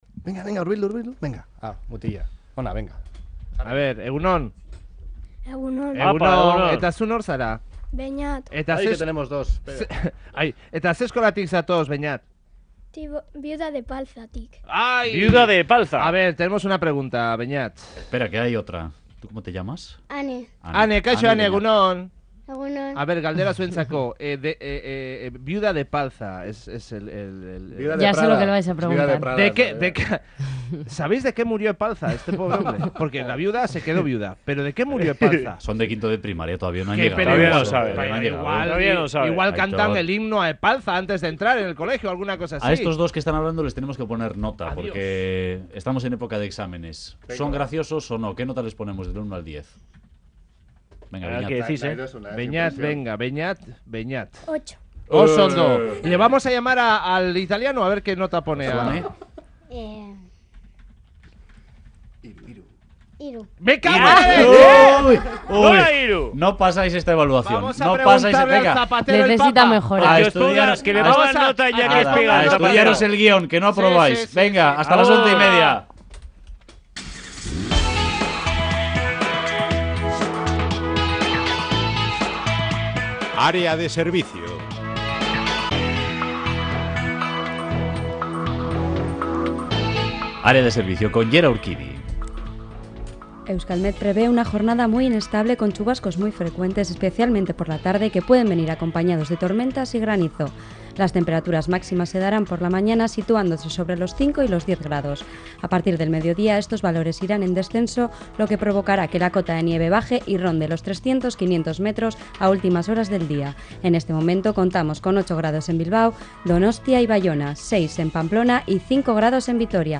Radio Euskadi BOULEVARD 'El Arte en guerra: 1938-1947, de Picasso a Dubuffet' en el Guggenheim Última actualización: 12/03/2013 11:52 (UTC+1) Éste próximo sábado se inaugura en el Museo Guggenheim Bilbao la exposición 'El arte en guerra: 1938 – 1947, de Picasso a Dubuffet'.